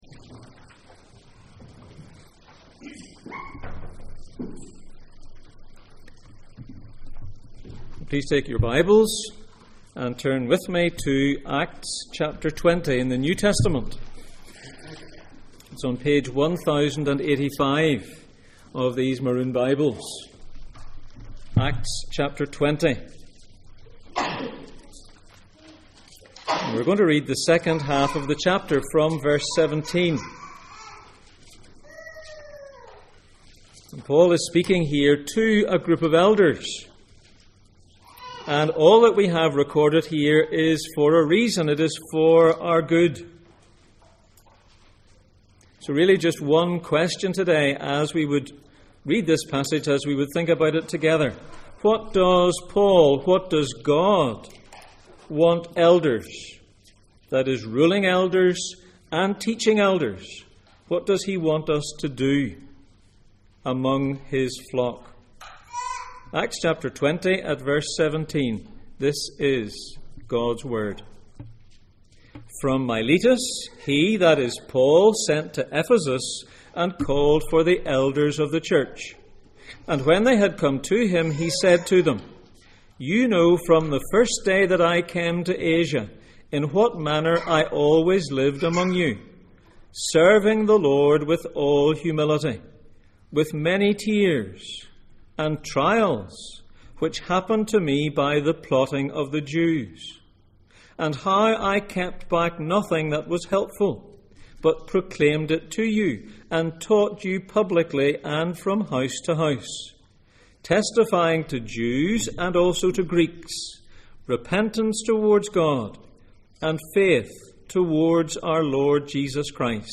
Passage: Acts 20:17-38, 2 Corinthians 4:7, John 17:11-17 Service Type: Sunday Morning %todo_render% « A Blind man sees Religion